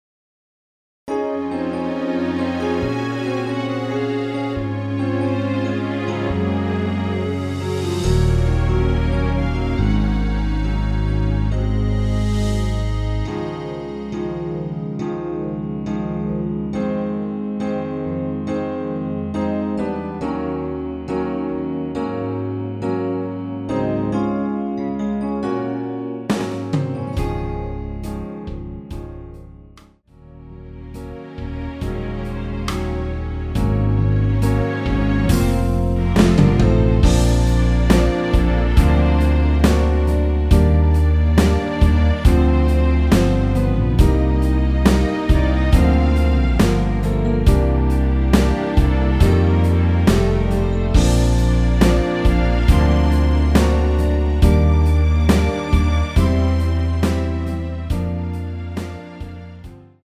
원키에서(-3)내린 MR입니다.
Db
음질도 고퀄이네요.
앞부분30초, 뒷부분30초씩 편집해서 올려 드리고 있습니다.